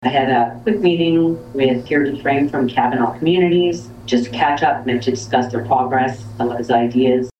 Arnprior Mayor Lisa McGee can’t be accused of not being proactive- a fact she spoke to in her Mayor’s Report to Town Council Monday (February 24th).
feb-24-lisa-mcgee-mayors-report-1.mp3